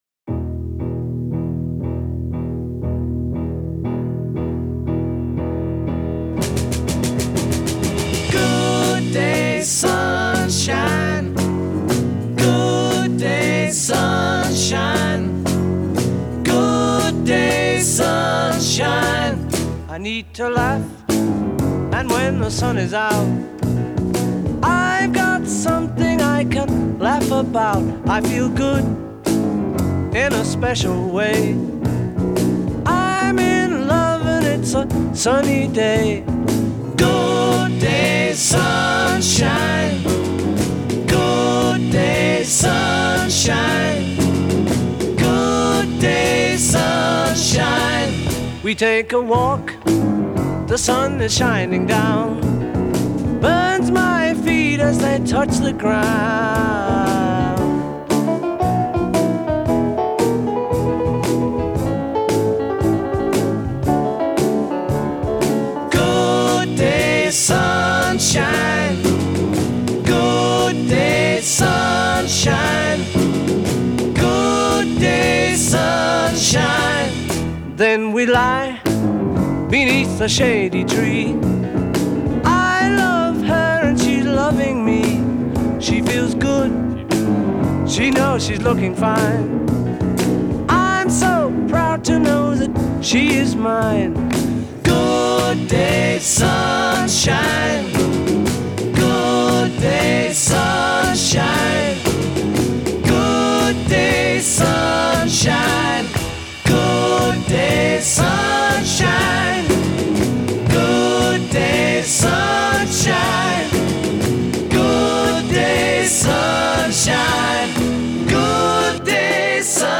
their use of piano is their most underrated.